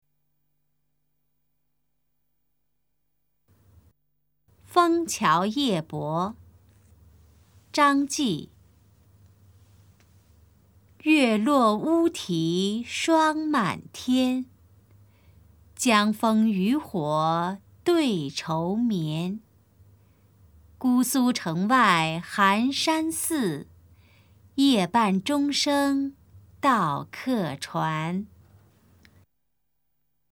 誦讀錄音